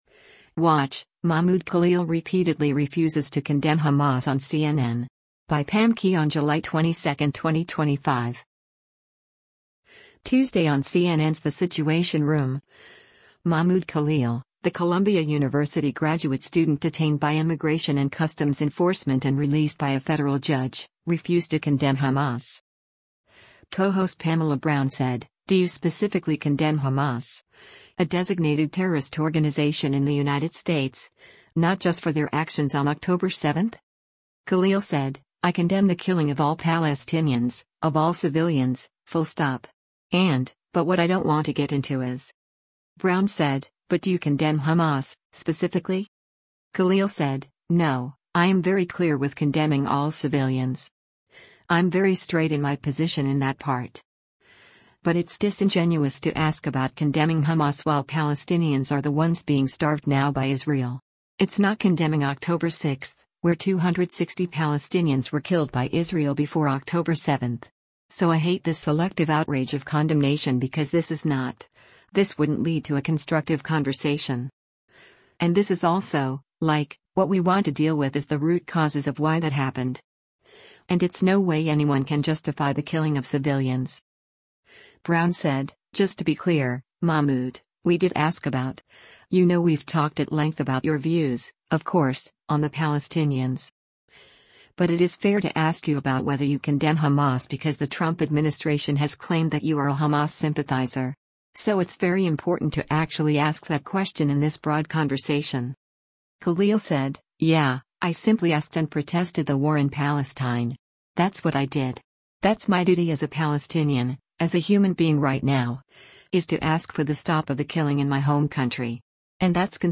Tuesday on CNN’s “The Situation Room,” Mahmoud Khalil, the Columbia University graduate student detained by Immigration and Customs Enforcement  and released by a federal judge, refused to condemn Hamas.